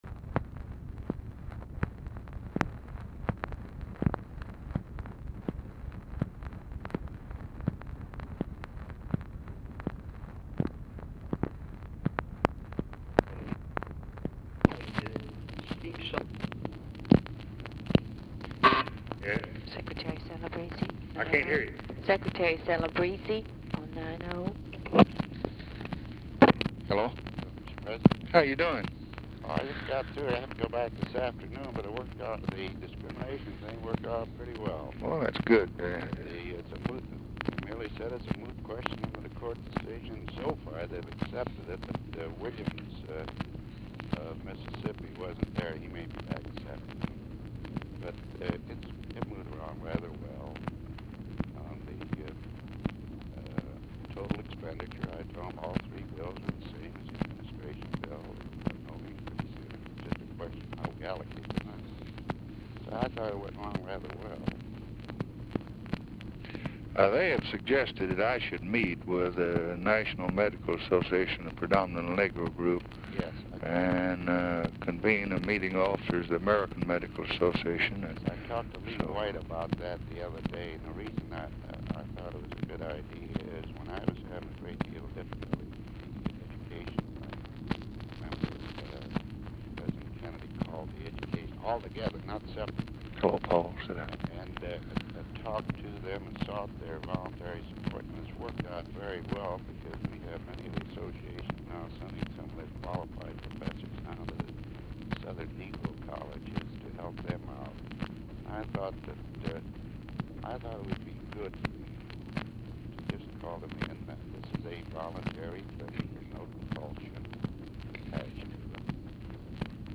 Telephone conversation
POOR SOUND QUALITY; CELEBREZZE DIFFICULT TO HEAR
Format Dictation belt